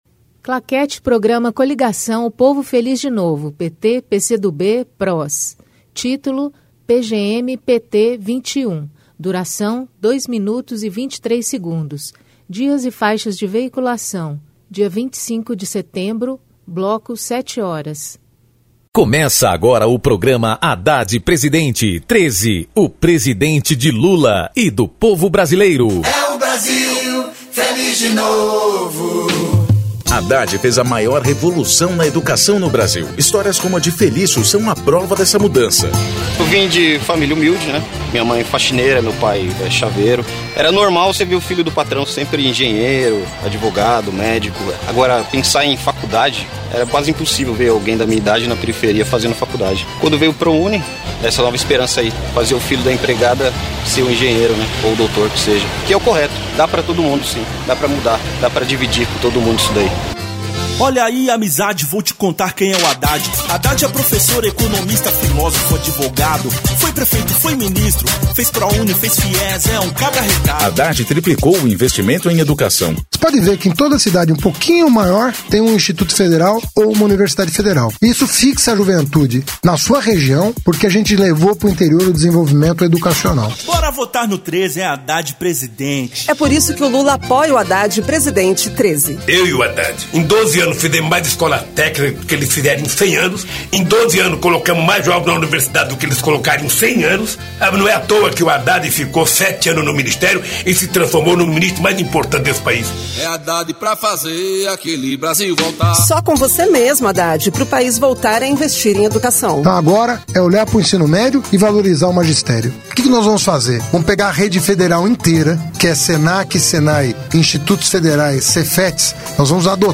TítuloPrograma de rádio da campanha de 2018 (edição 21)
Descrição Programa de rádio da campanha de 2018 (edição 21) - 1° turno